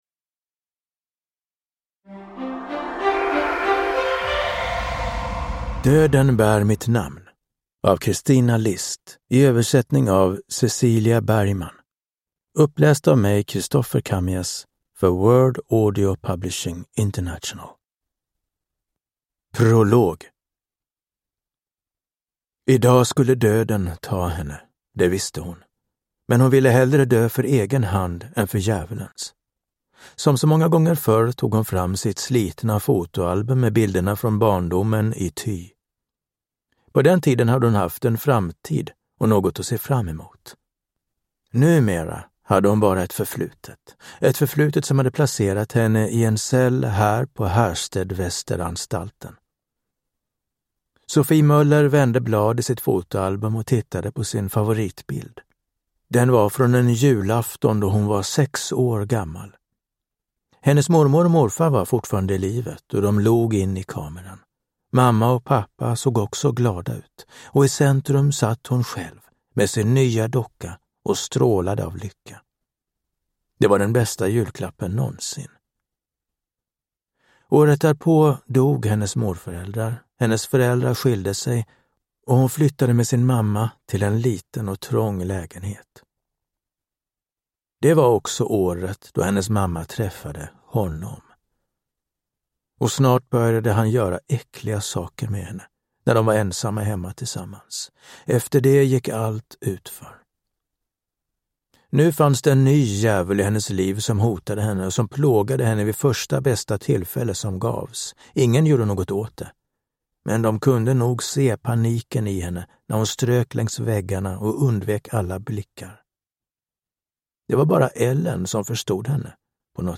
Döden bär mitt namn – Ljudbok